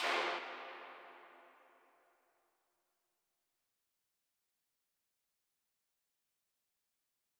MDMV3 - Hit 4.wav